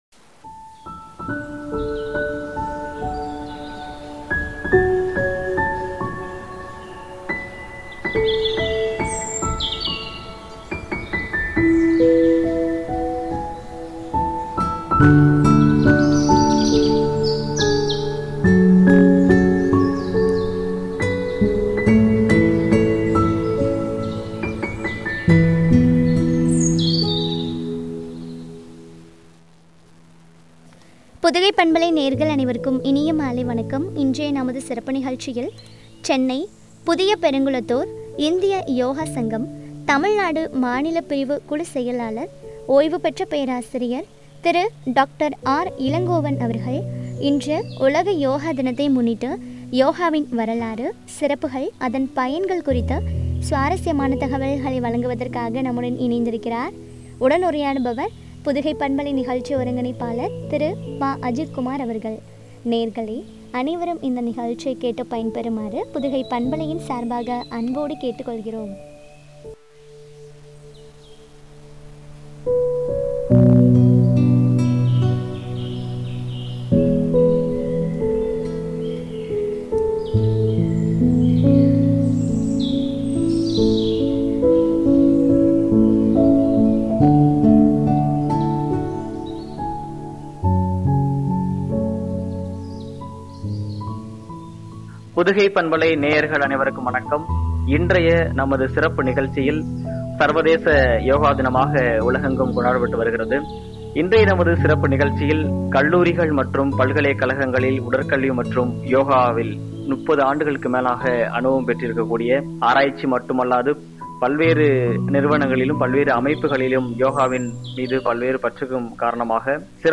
பயன்கள் குறித்து வழங்கிய உரையாடல்.